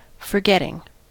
forgetting: Wikimedia Commons US English Pronunciations
En-us-forgetting.WAV